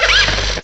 cry_not_blitzle.aif